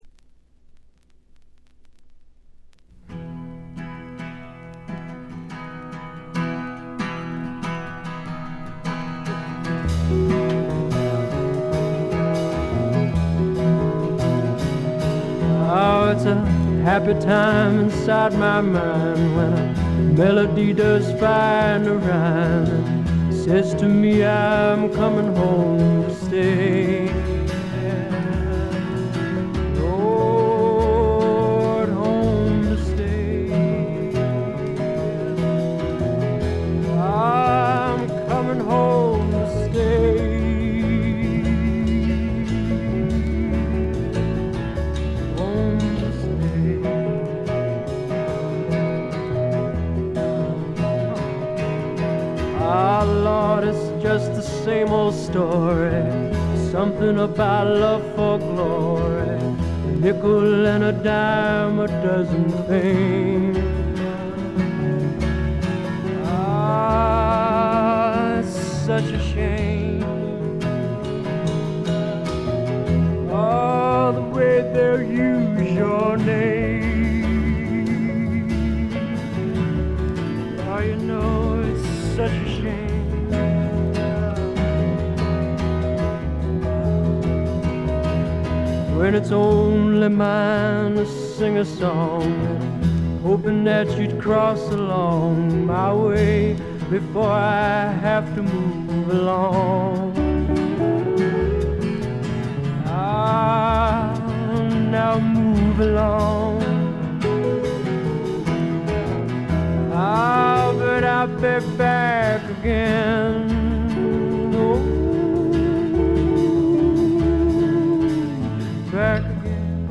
軽微なバックグラウンドノイズ、チリプチ少し。
内省的で沈うつなムードに包まれたひりひりする感覚は一度味わったら思い切り癖になります。
アシッド・フォーク好きならもちろん基本ですが、一般のシンガー・ソングライター・ファンにも強力にオススメできるものです。
試聴曲は現品からの取り込み音源です。